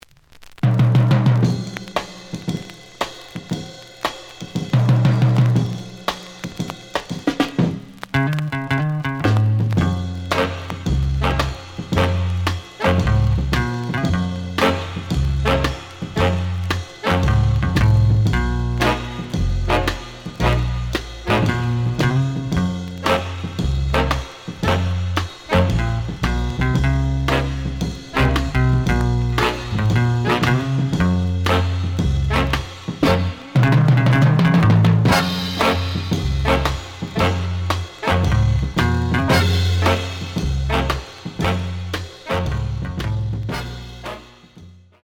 The audio sample is recorded from the actual item.
●Genre: Latin